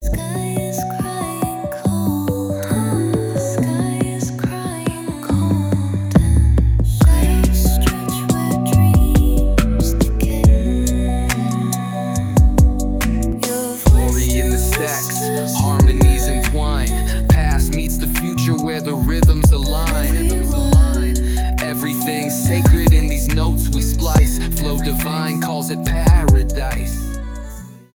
спокойные , дуэт
rnb , рэп